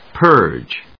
/pˈɚːdʒ(米国英語), pˈəːdʒ(英国英語)/